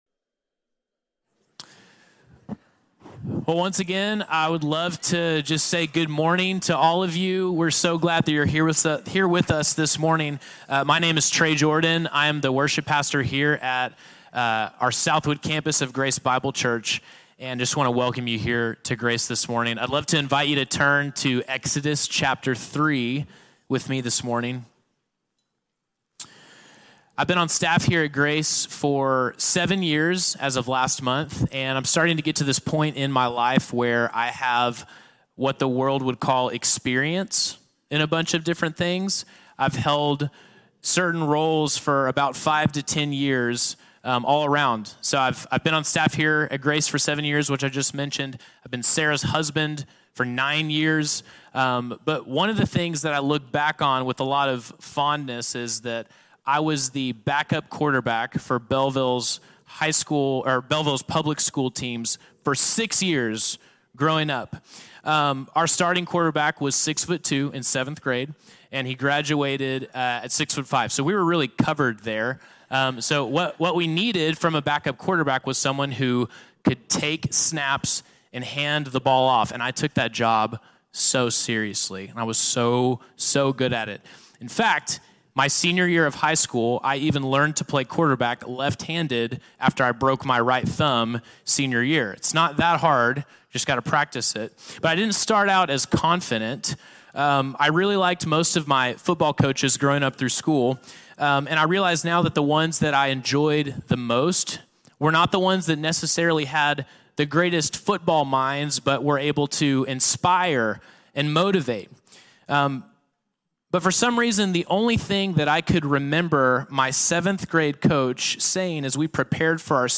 The Call of Moses: Where Fear Meets Truth | Sermon | Grace Bible Church